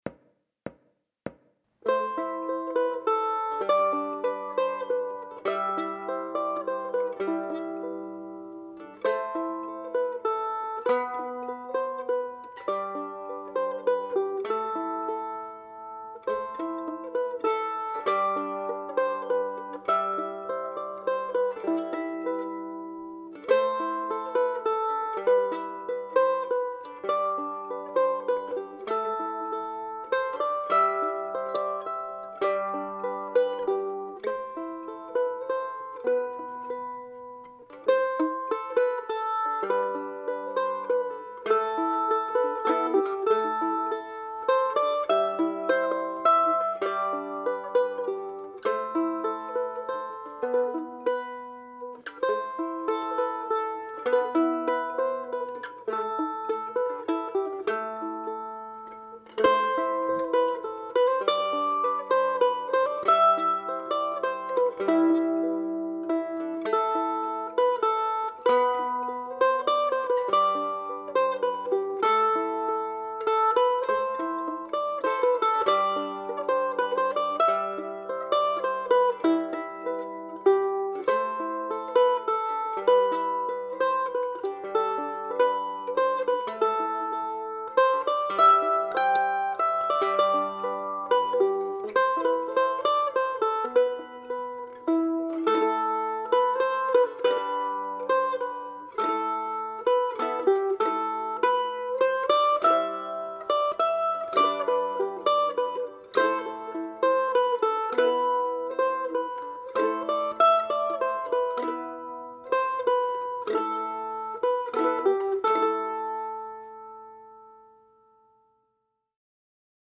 MANDOLIN SOLO Celtic/Irish, Mandolin Solo
DIGITAL SHEET MUSIC - MANDOLIN SOLO